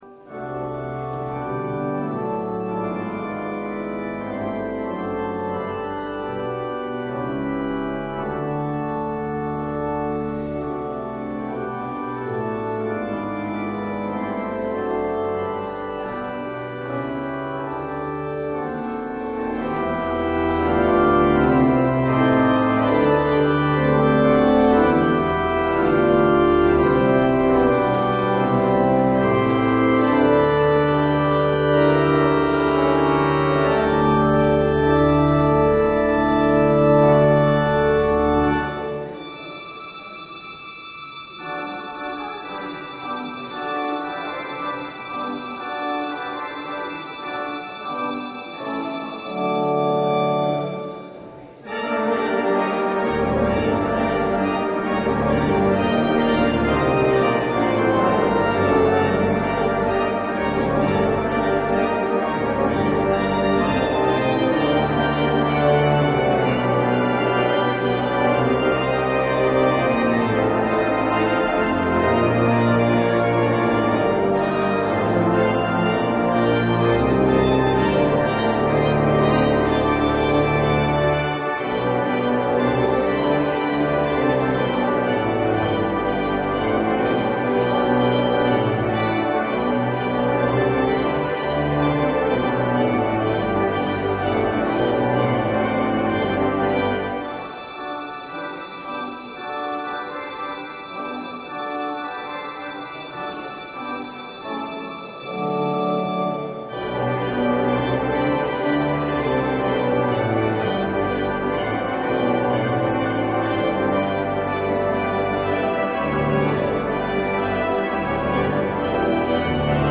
 Luister deze kerkdienst hier terug: Alle-Dag-Kerk 15 augustus 2023 Alle-Dag-Kerk https
Het openingslied is: Psalm 67. Het slotlied is: Lied 457 (LvdK).